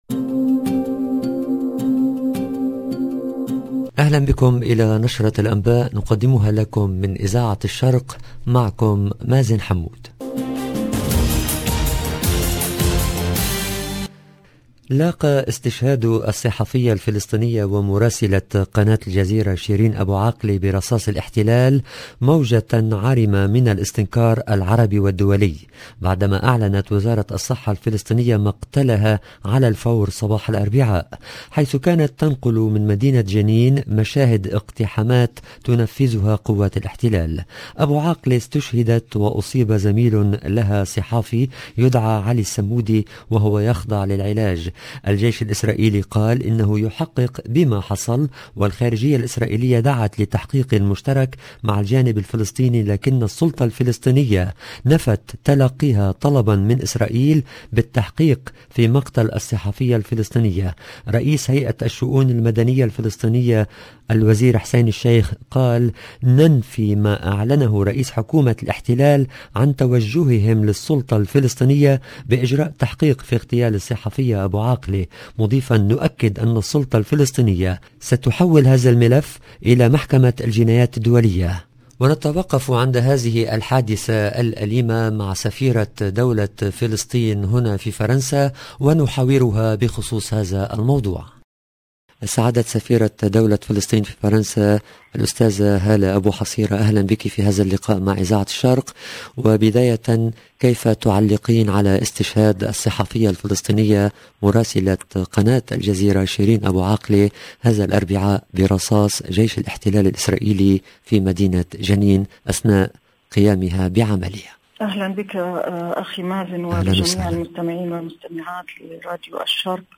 ادانات عربية واسلامية ودولية لاستشهاد شيرين ابو عاقلة الصحفية الفلسطينية برصاص الاحتلال اثناء عملها في جنين لنقل وقائع الاقتحامات مقابلة مع سفيرة فلسطين في فرنسا هالة ابو حصيرة حول استشهاد ابو عاقلة